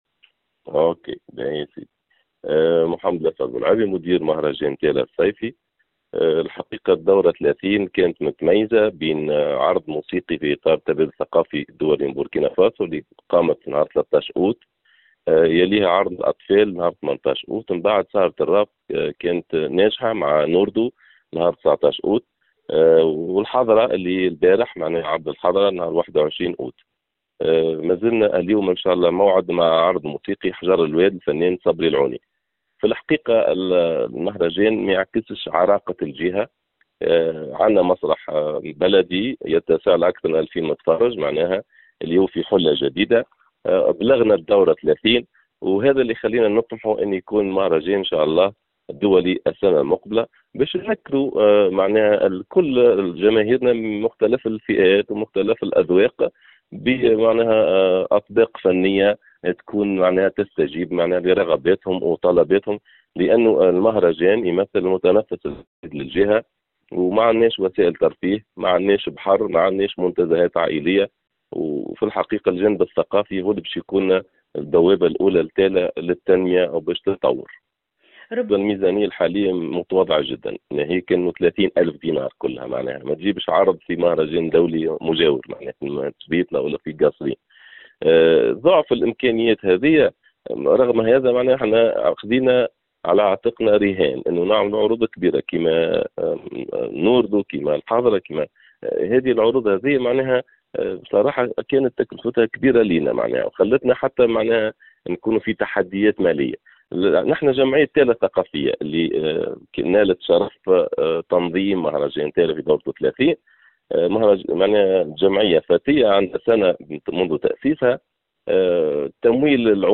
تواصل فعاليات المهرجان الصيفي بتالة (تصريح+صور)